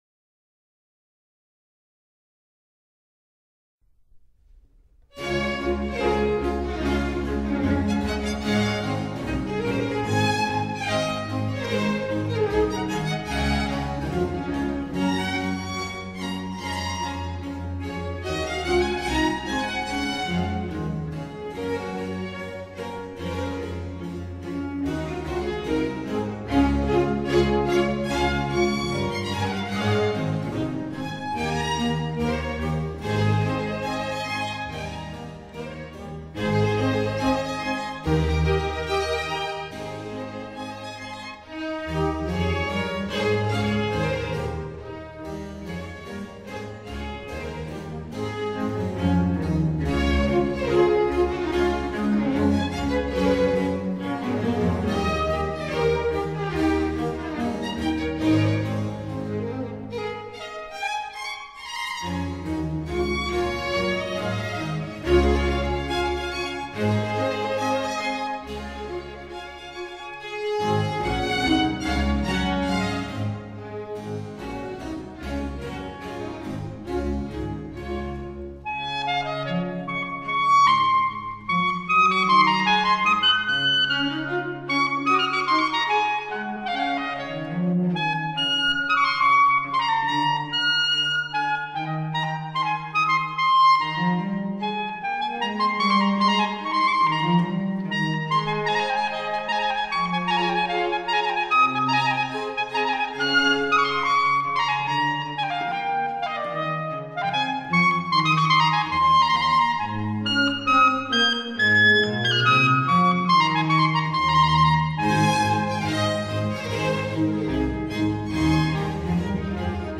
Concerto for clarinet and small orchestra No.4 in D major
Orchestra dell`Accademia Nazionale di Santa Cecilia